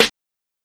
Snares
Reminder Snare.wav